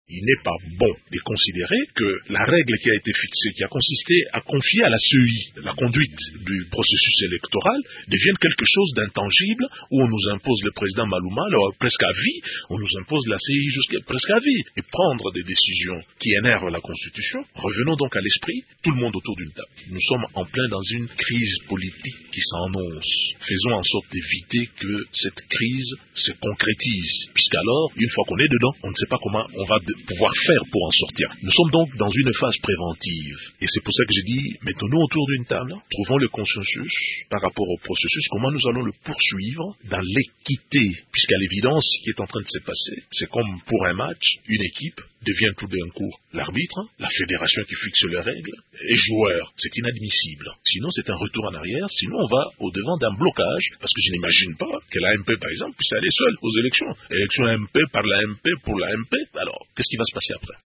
François Mwamba, secrétaire général du MLC
Radio Okapi a recueilli les propos de François Mwamba, secrétaire général du MLC, dont voici un extrait: